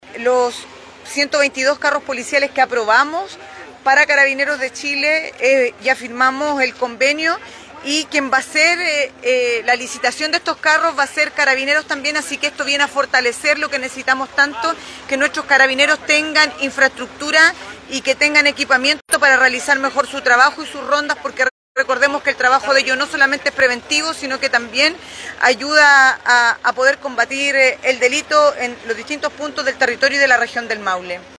La gobernadora Cristina Bravo afirmó que, a esta entrega, se sumarán 122 carros policiales a futuro, cuyo financiamiento ya fue aprobado por el Consejo Regional del Maule y serán los mismos carabineros, los encargados de adquirirlos.